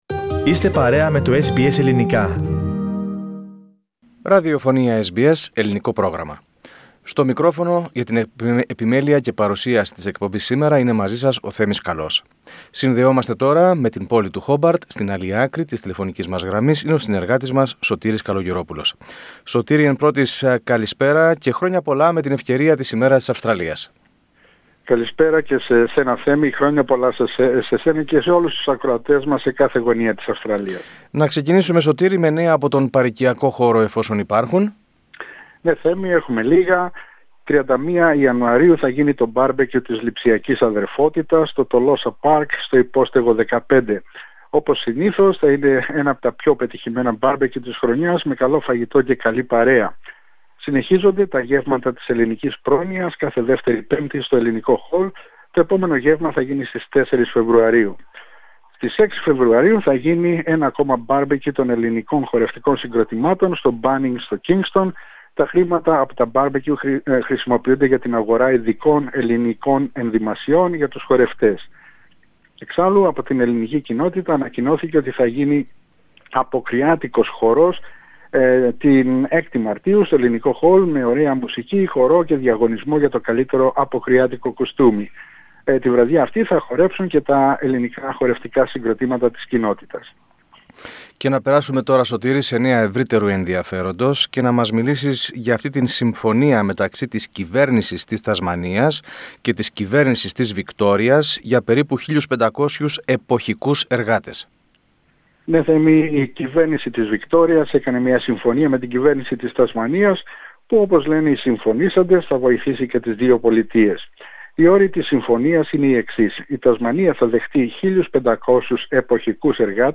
Στην εβδομαδιαία ανταπόκριση από την Τασμανία: η συμφωνία με την Βικτώρια για 1500 εποχικούς εργάτες, o εντοπισμός ενός σπάνιου είδους αστακο-καραβίδας και ένα κυνήγι θησαυρού από το μουσείο ΜΟΝΑ